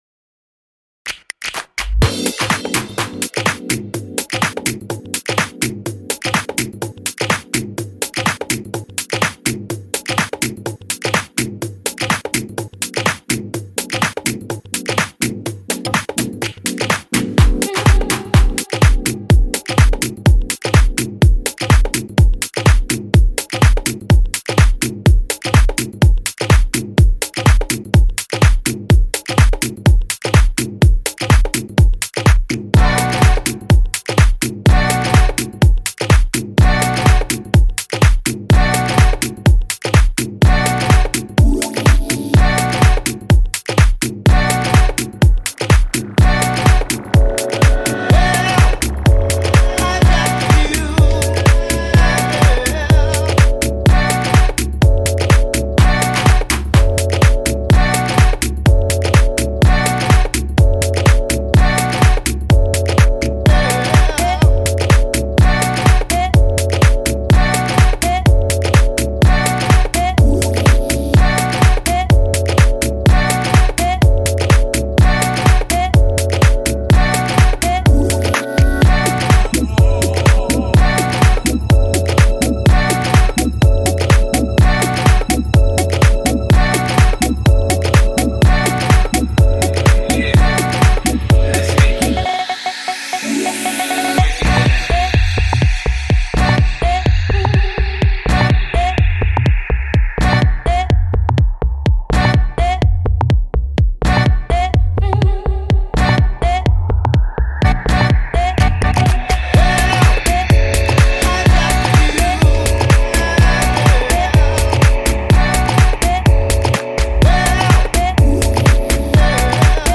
a proper jackin house